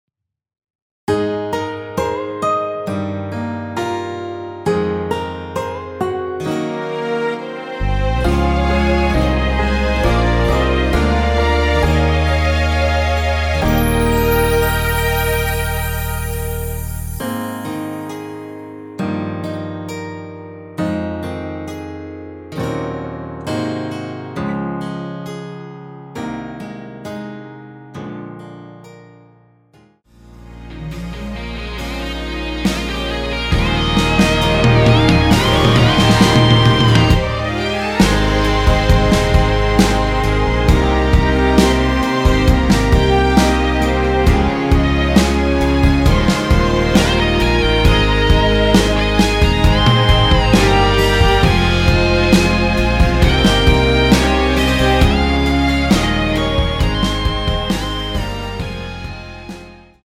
원키에서(+5)올린 MR입니다.
Ab
앞부분30초, 뒷부분30초씩 편집해서 올려 드리고 있습니다.
중간에 음이 끈어지고 다시 나오는 이유는